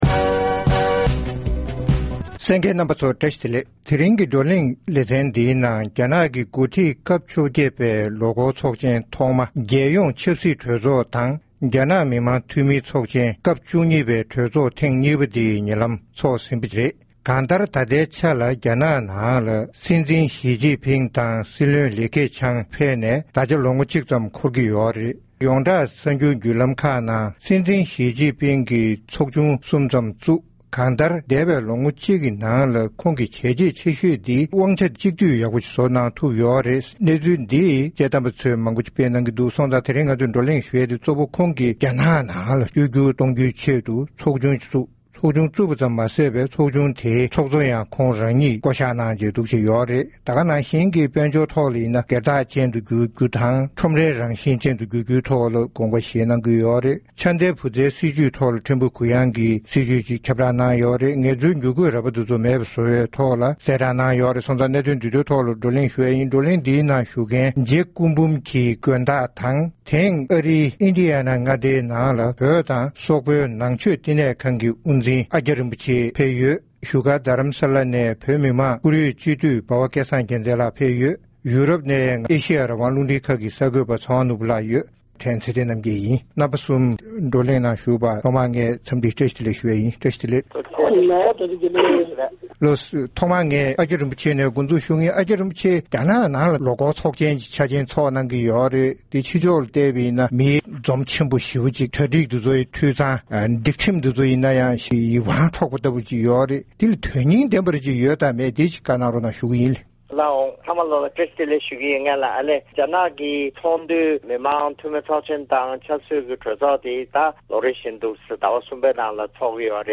བགྲོ་གླེང་གནང་ཞིག་གསན་རོགས་གནང་༎